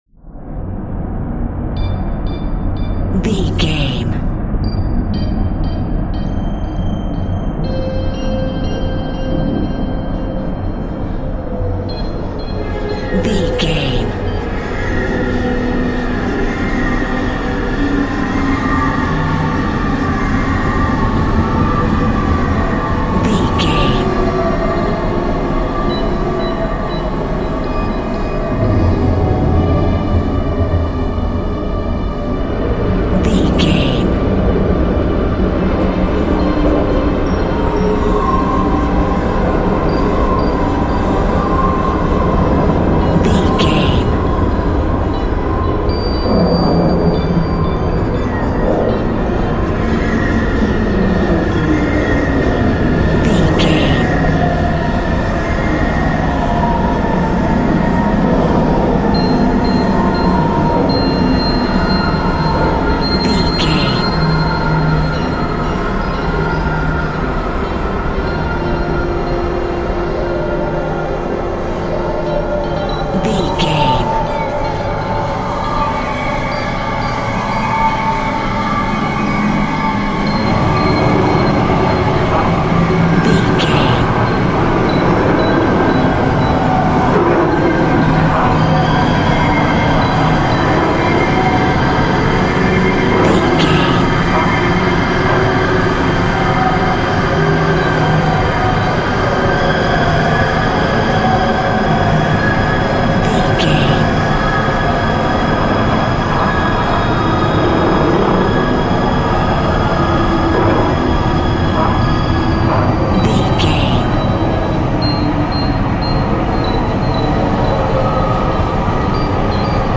Atonal
scary
ominous
suspense
eerie
horror
synth
keyboards
ambience
pads
eletronic